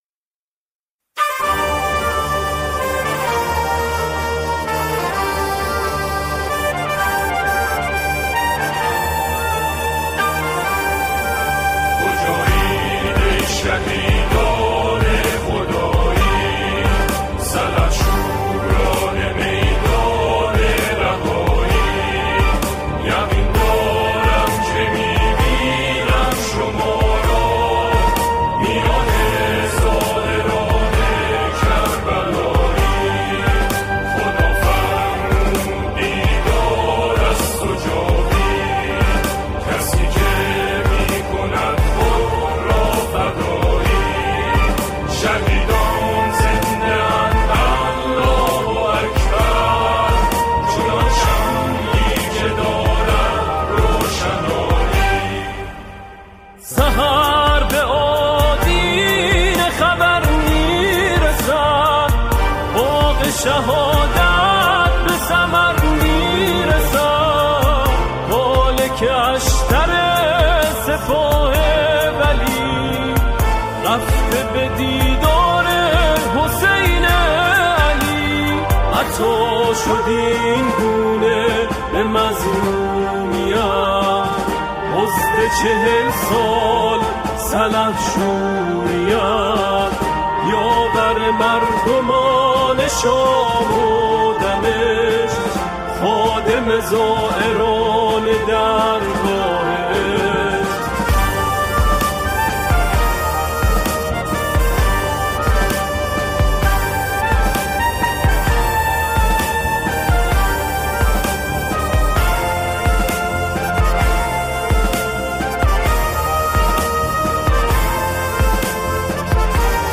گروهی از همخوانان